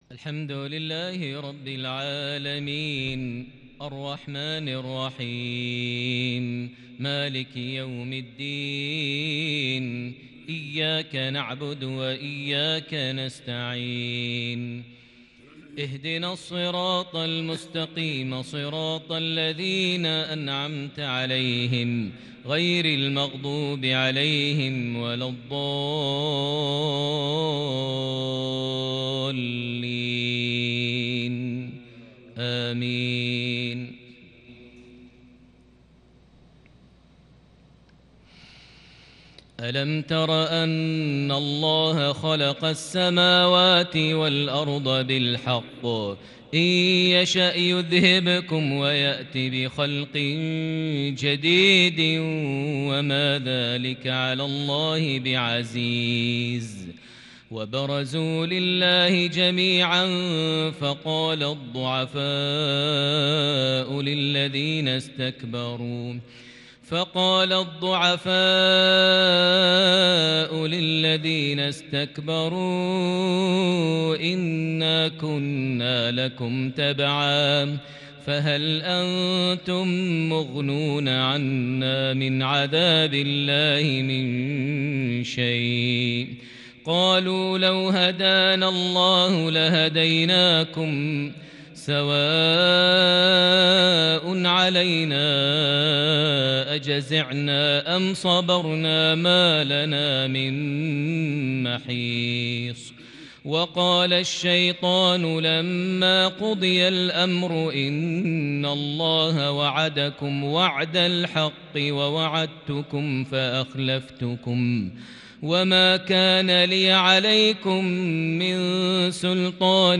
تلاوة خيالية فريده من سورة إبراهيم (19- 27) | عشاء الجمعة 9 محرم 1442هـ > 1442 هـ > الفروض - تلاوات ماهر المعيقلي